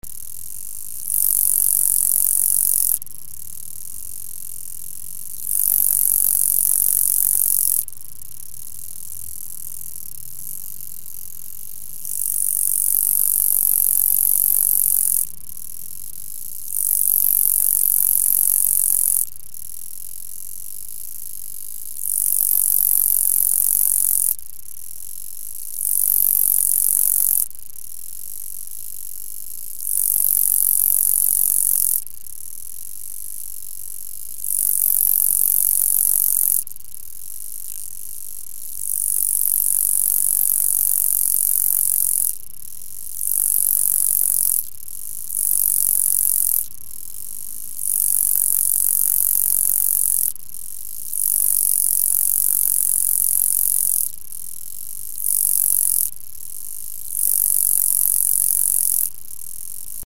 Gampsocleis glabra Hbst. - Кузнечик
оголенный показать фото показать фото показать фото показать фото показать фото показать фото показать фото показать фото показать фото показать фото